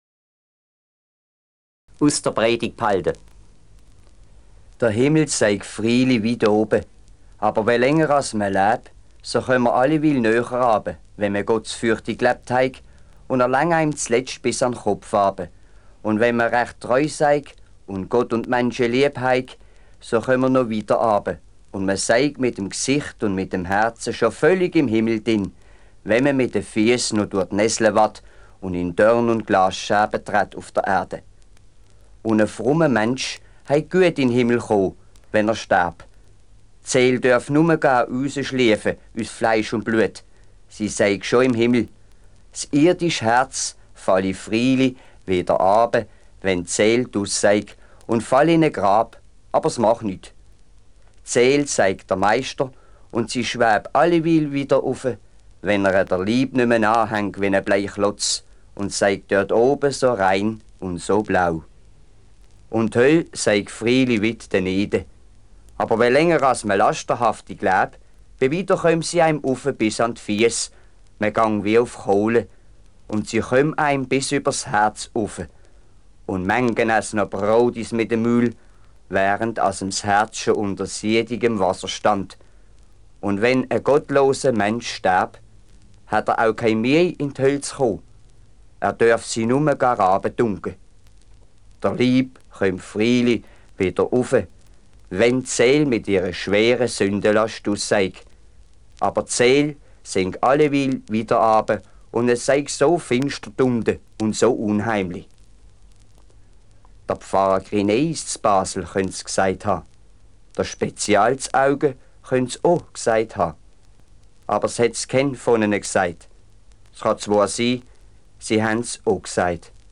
LP: Johann Peter Hebel, Alemannische Gedichte und Lieder, 1975;
Sprecher: Burgfestspiele Rötteln e. V.